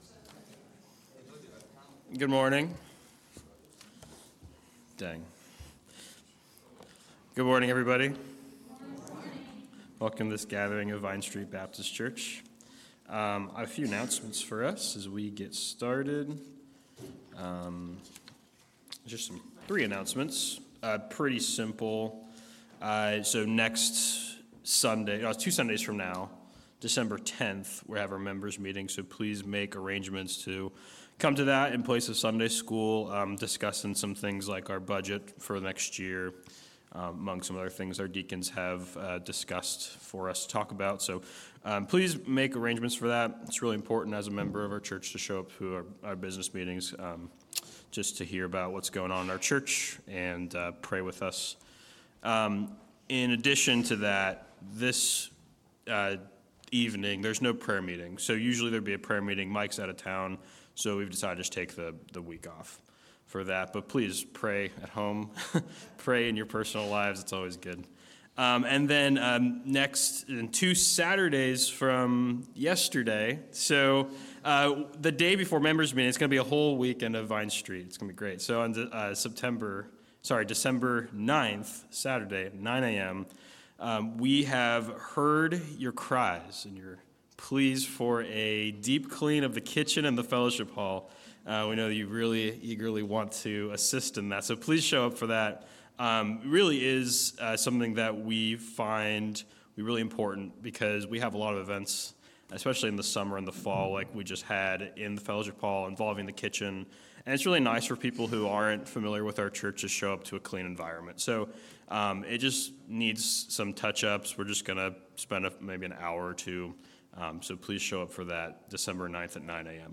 November 26 Worship Audio – Full Service